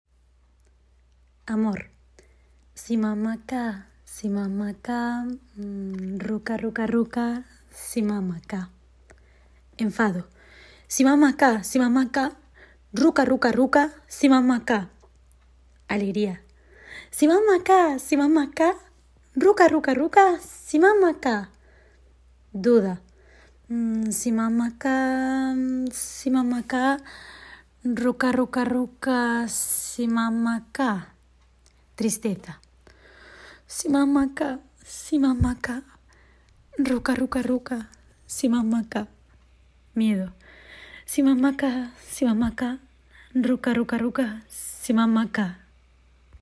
AMOR, ENFADO, ALEGRÍA, DUDA, TRISTEZA, MIEDO
Emociones de la voz.
emociones-voz.mp3